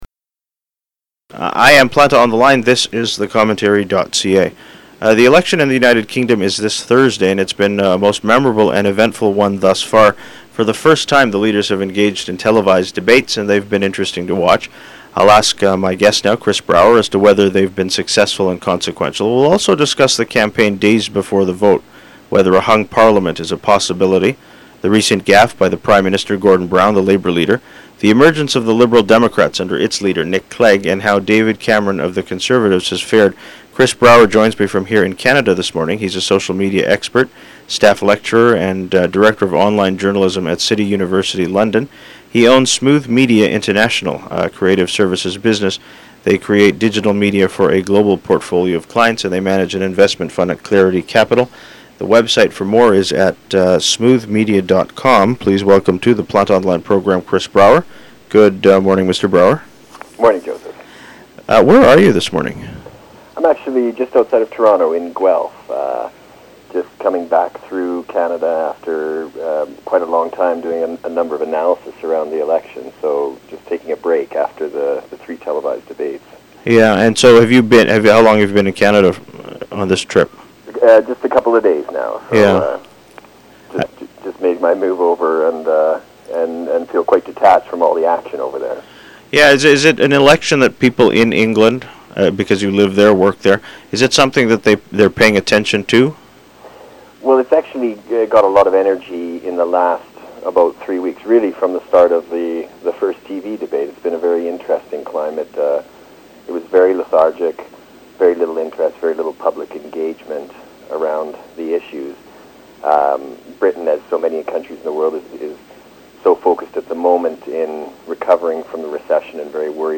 Text of introduction